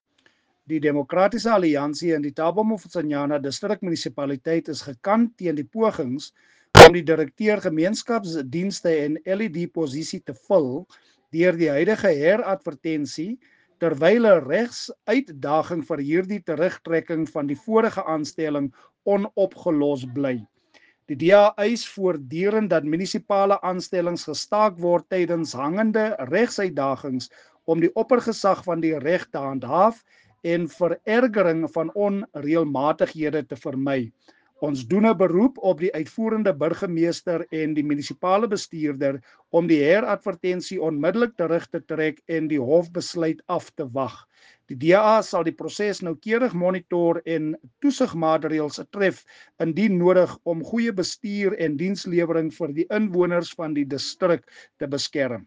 Afrikaans soundbite by Cllr Marius Marais